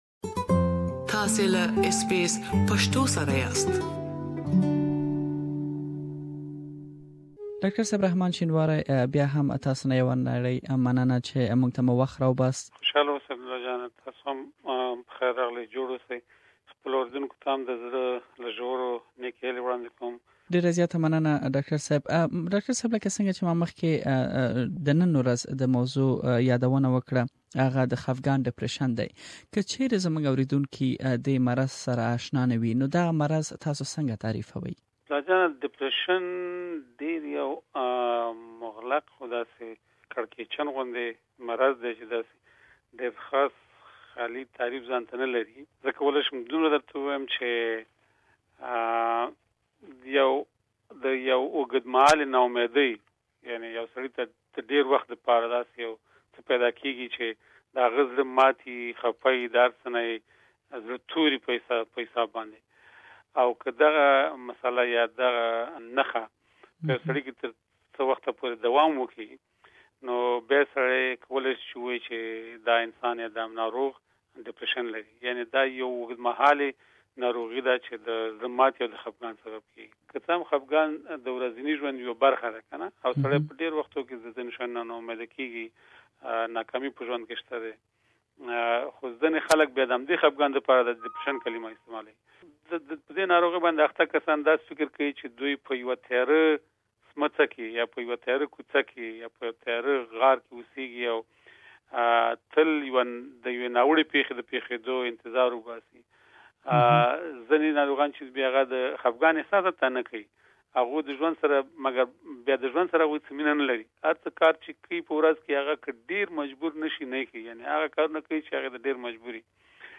Please listen to the first part of the interview here Share